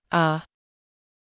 母音/ʌ/は、日本語の「ア」に近い音です。
少し低くて硬いイメージの音です。
母音/ʌ/のみの発音
母音uhのみの発音.mp3